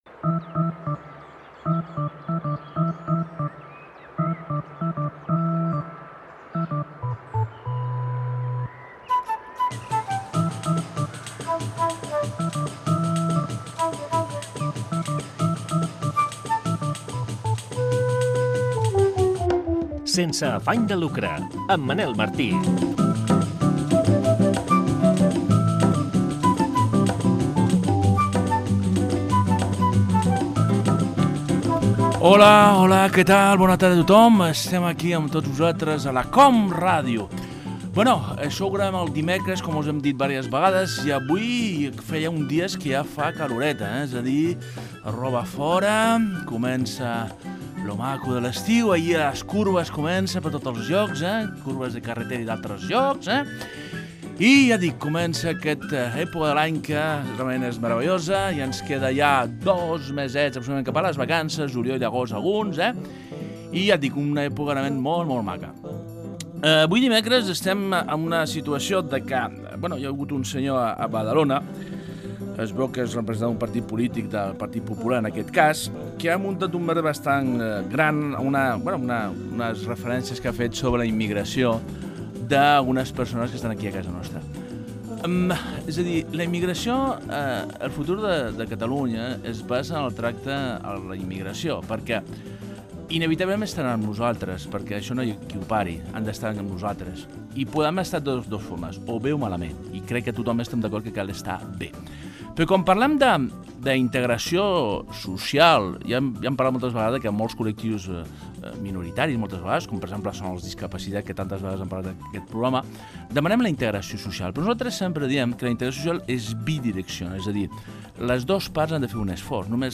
Divulgació
FM
Fragment extret de l'arxiu sonor de COM Ràdio.